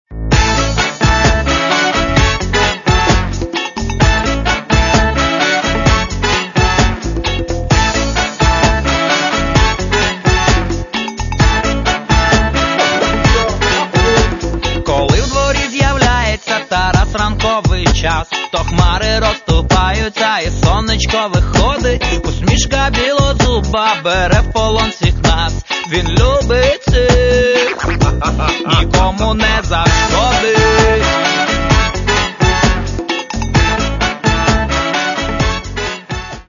Каталог -> Рок та альтернатива -> Поп рок